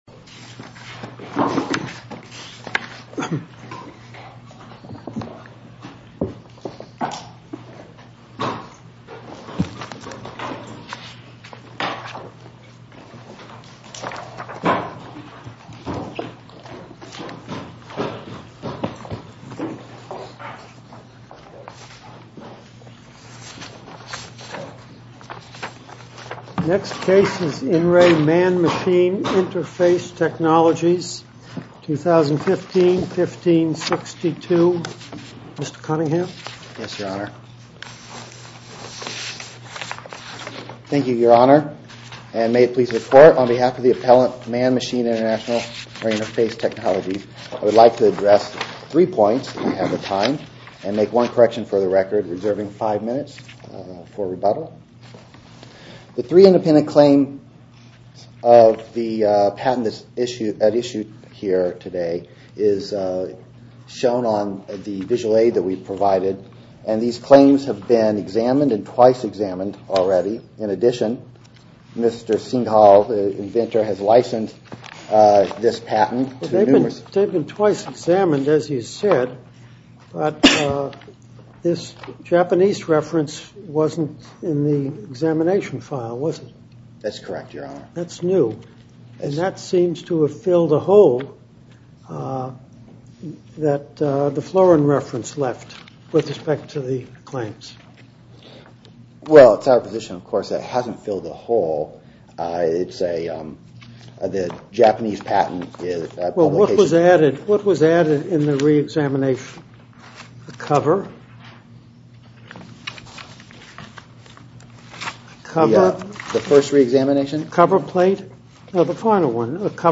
To listen to more oral argument recordings, follow this link: Listen To Oral Arguments.